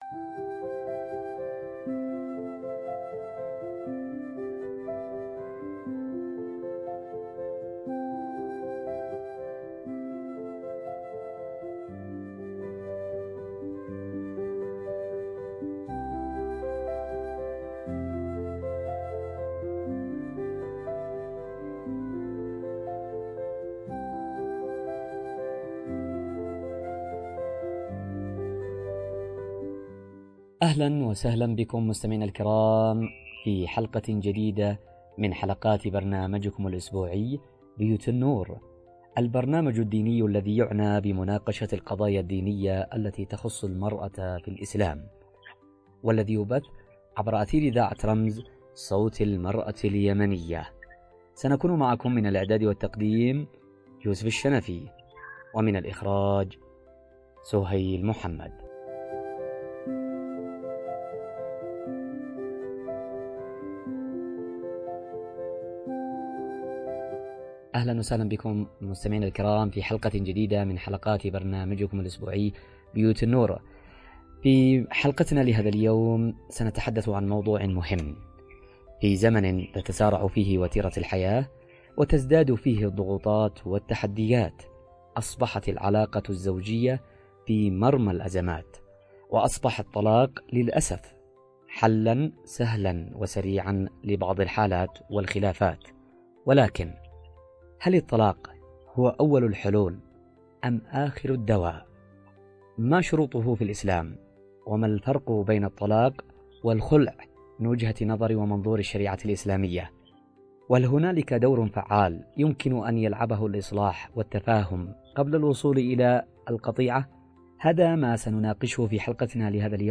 نقاش ديني و شرعي